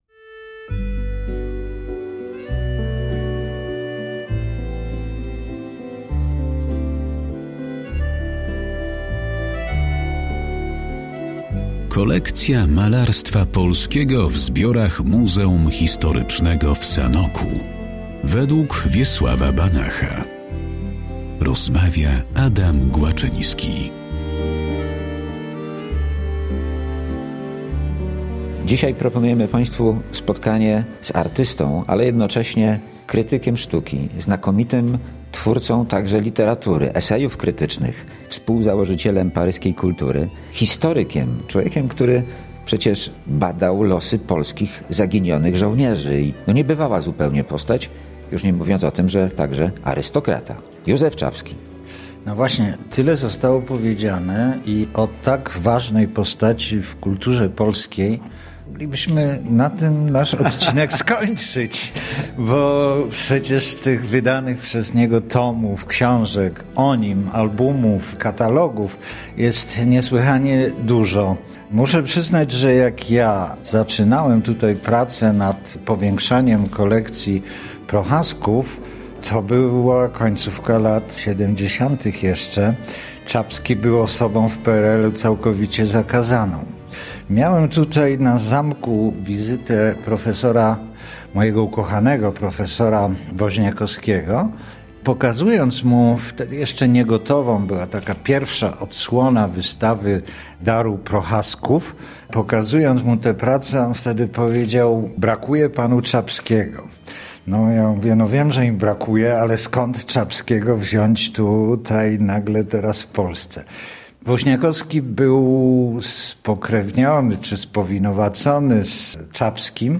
O twórczości Józefa Czapskiego oraz jego pracach znajdujących się w Muzeum Historycznym w Sanoku rozmawiają historyk sztuki